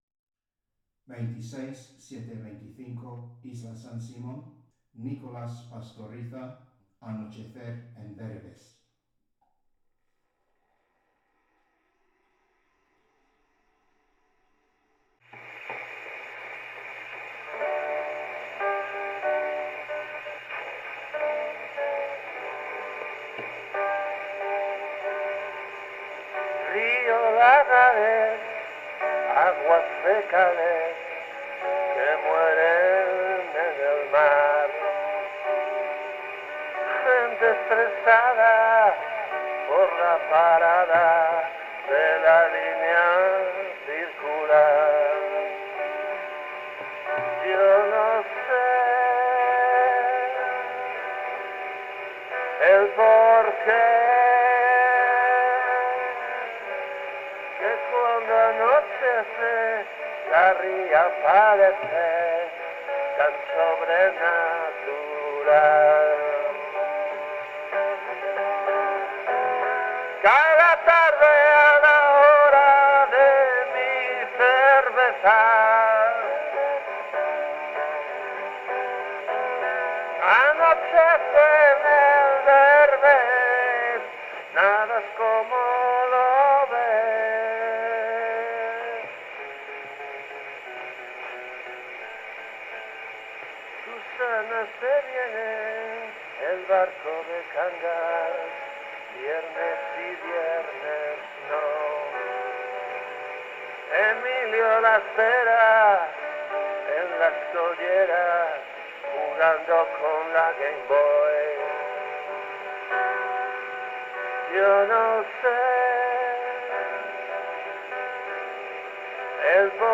Original sound from the phonographic cylinder.
Recording place: Espazo Cafetería